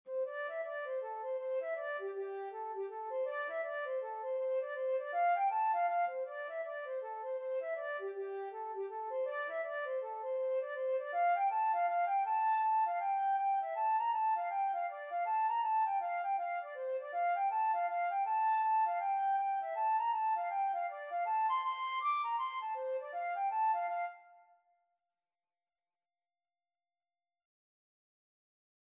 Free Sheet music for Flute
4/4 (View more 4/4 Music)
G5-D7
F major (Sounding Pitch) (View more F major Music for Flute )
Traditional (View more Traditional Flute Music)